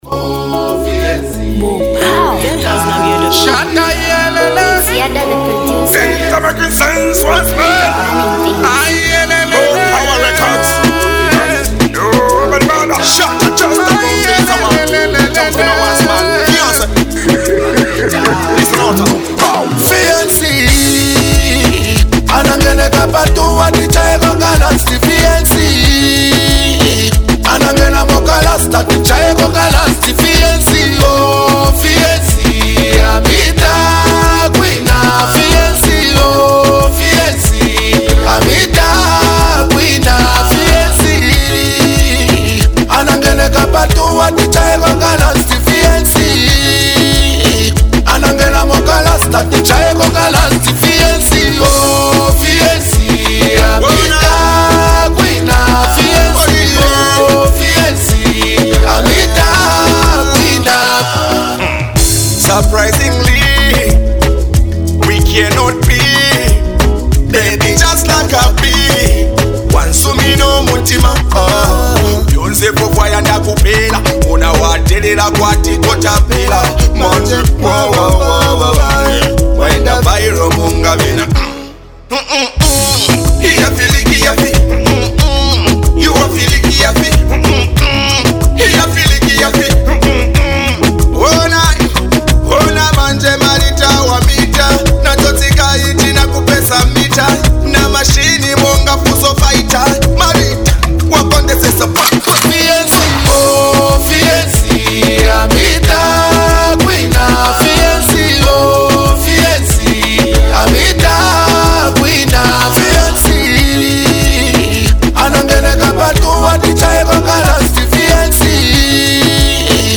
An emotional banger